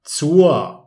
ซัวร์